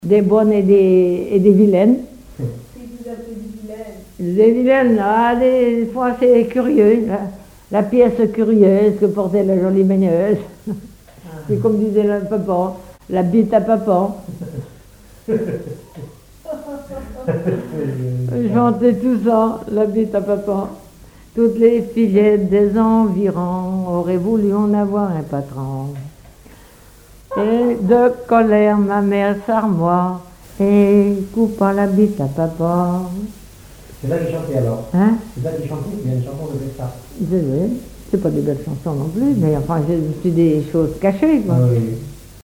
chanteur(s), chant, chanson, chansonnette
collecte en Vendée
Témoignages et chansons traditionnelles